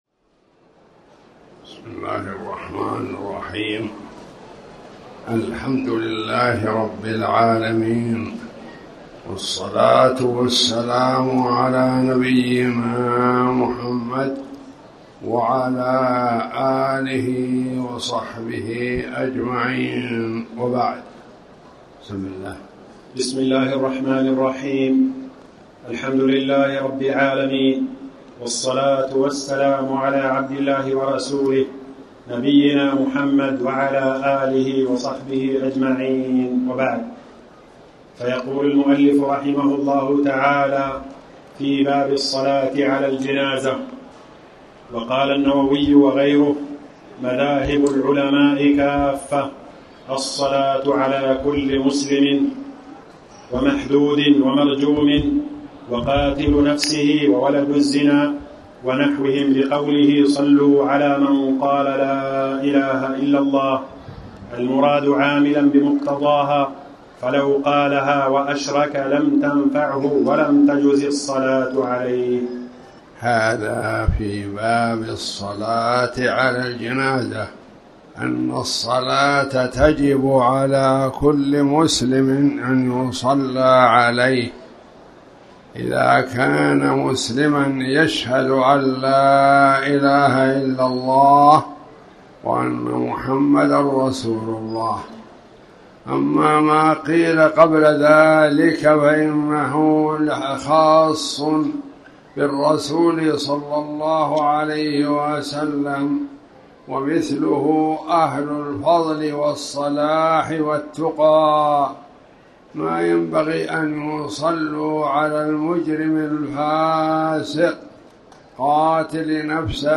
تاريخ النشر ١٩ ربيع الثاني ١٤٣٩ هـ المكان: المسجد الحرام الشيخ